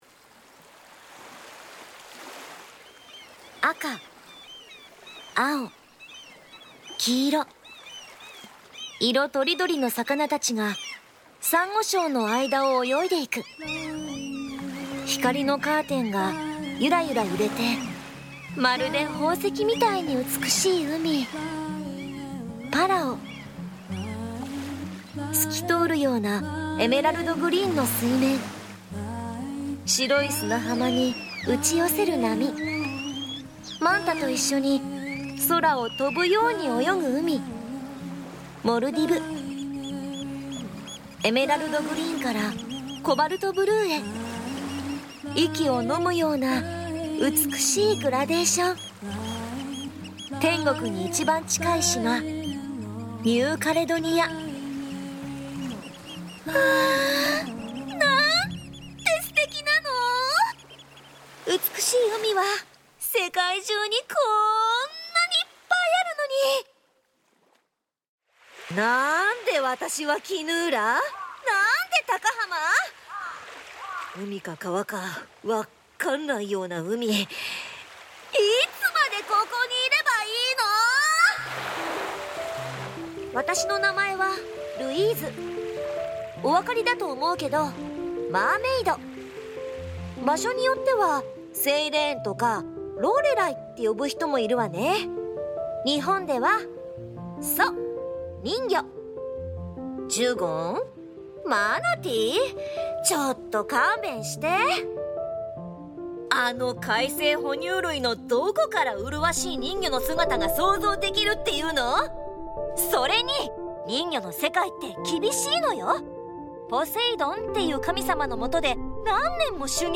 高浜市観光協会発のオリジナルアニメ＆ボイスドラマプロジェクト「推しタカ」9月のボイスドラマが配信開始！
実力派の声優が情感豊かに演じる、戦時下という困難な時代に咲いた、一途で純粋な愛をご堪能ください。